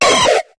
Cri de Griknot dans Pokémon HOME.